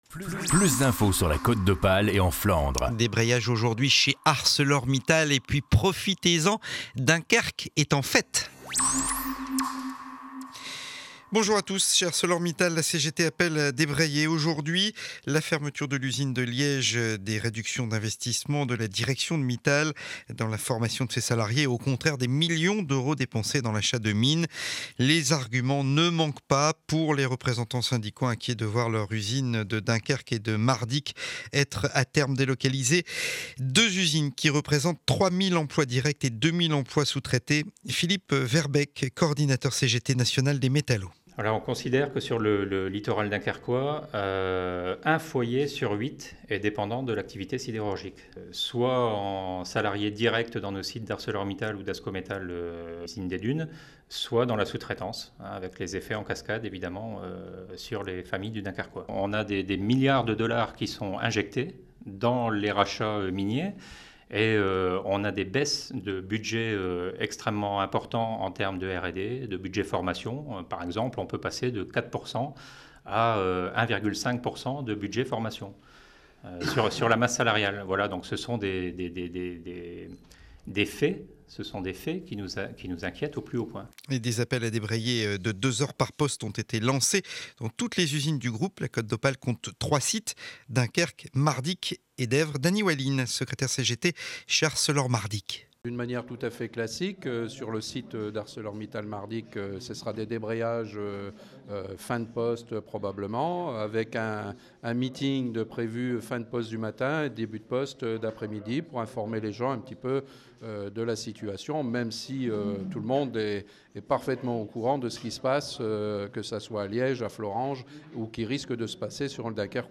Journal de 7h30 du mercredi 7 décembre édition de Dunkerque.